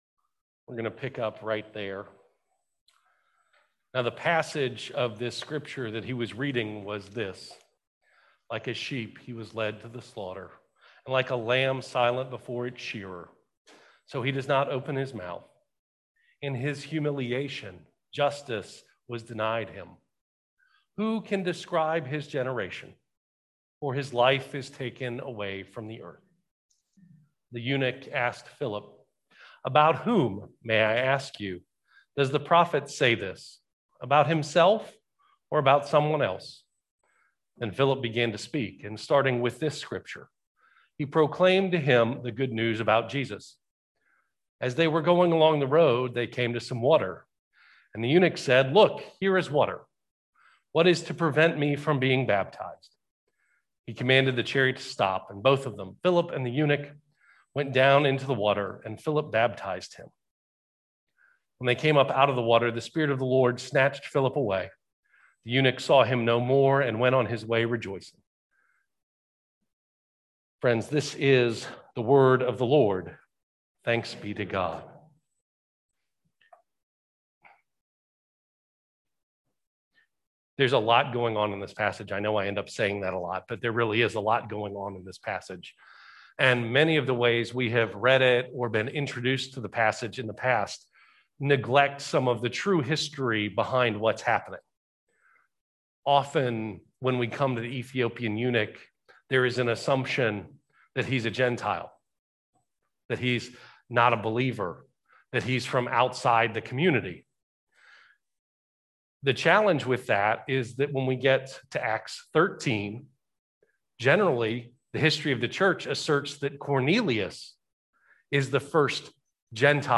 Worship 2021 April 25, 2021 - Invitation or Invitation Only?